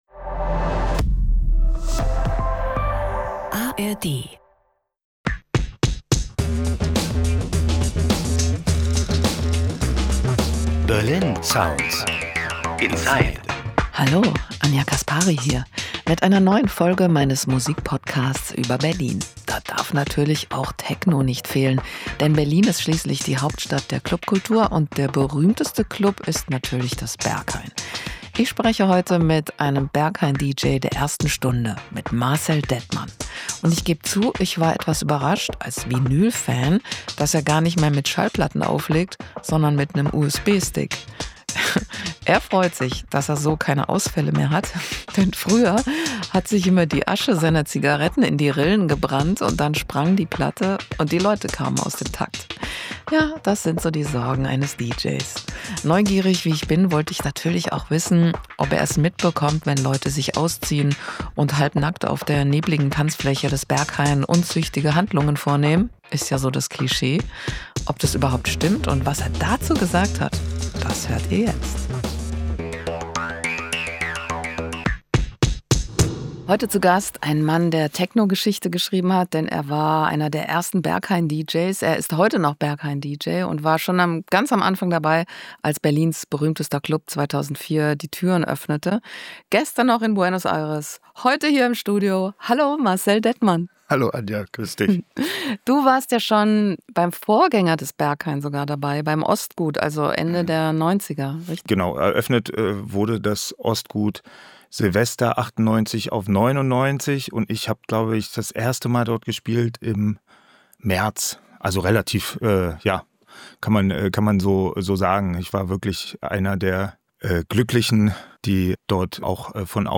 DJ Marcel Dettmann: König des Techno im Berghain ~ Berlin Sounds Inside – Der Musiktalk